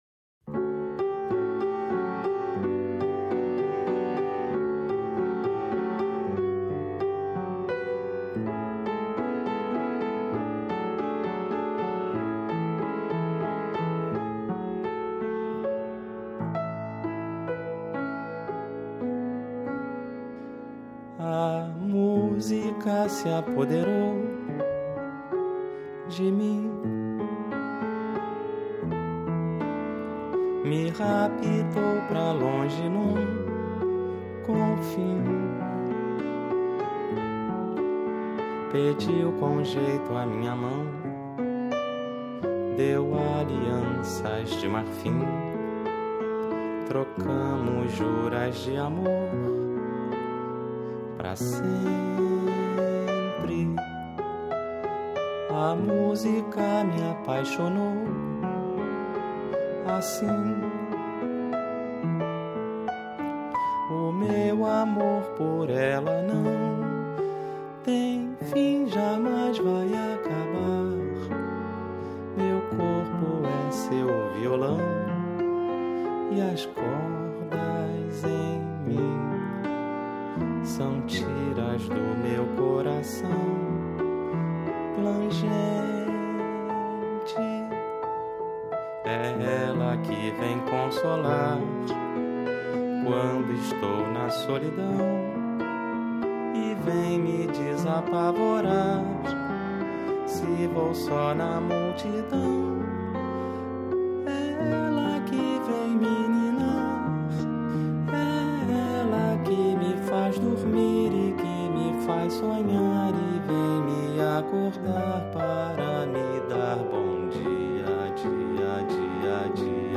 MPB Valsa Canção Lirismo